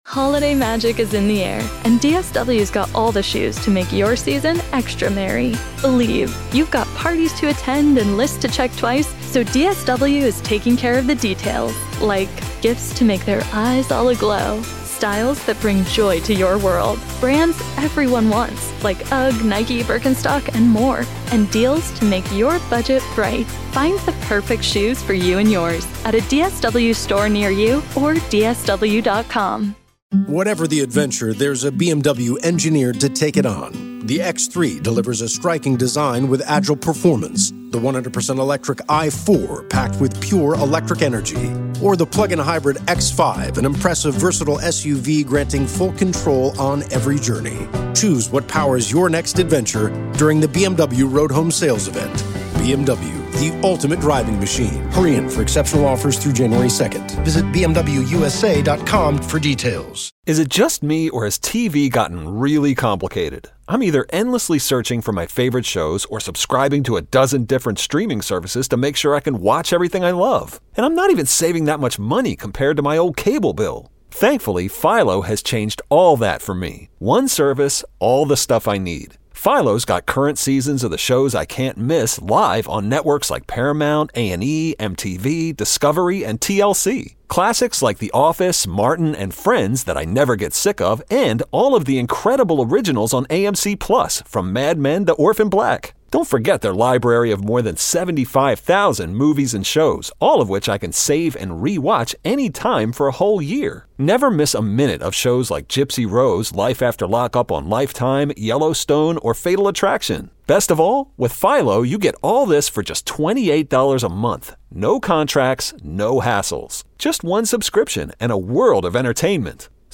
Calls about the Pirates payroll and negative reaction to the trade at 1B